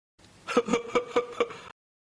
didi lacht 1 (wav, 367 KB)